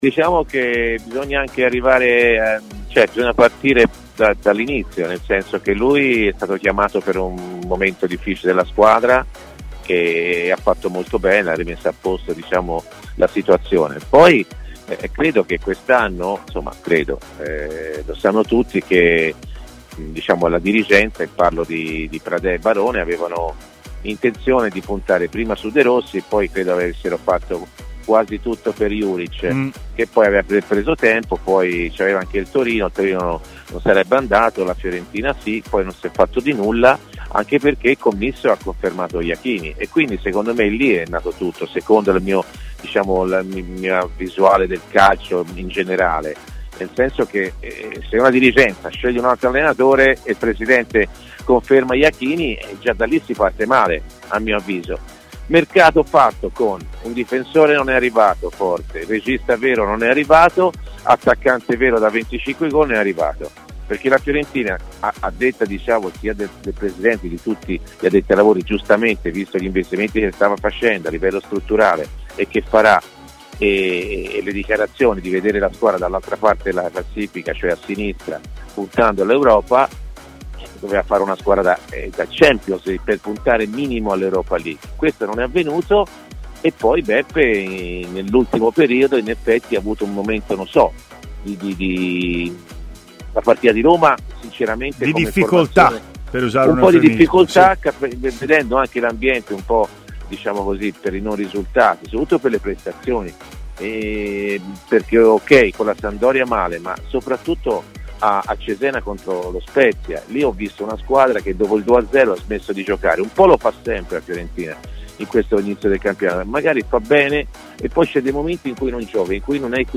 L'opinionista ed ex calciatore viola Antonio Di Gennaro è intervenuto in diretta ai microfoni di TMW Radio, nel corso della trasmissione Stadio Aperto, per parlare dei principali temi di attualità, tra i quali quelli legati al futuro della panchina della Fiorentina.